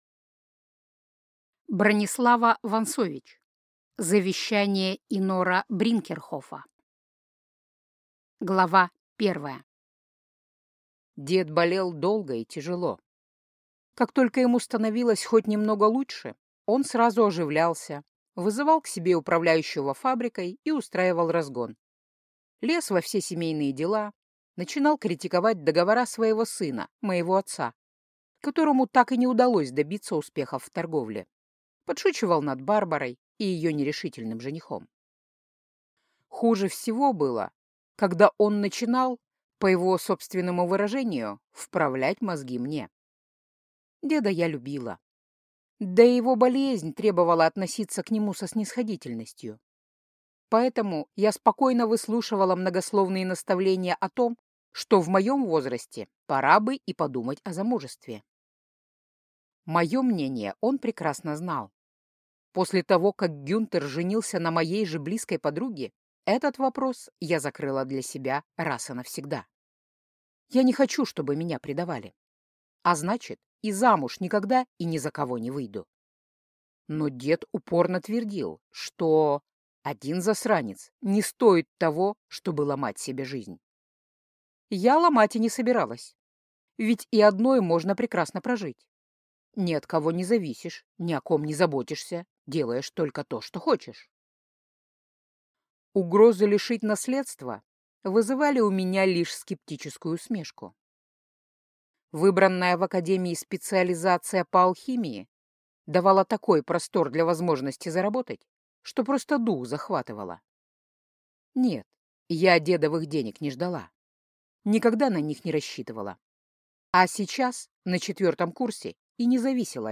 Аудиокнига Завещание инора Бринкерхофа - купить, скачать и слушать онлайн | КнигоПоиск